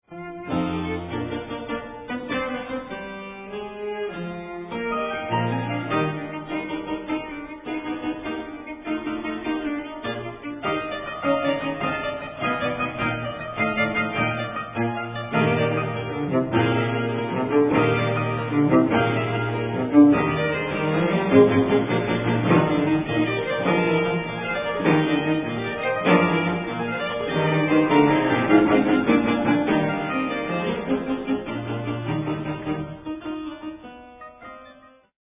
for Violin, Cello and Harpsichord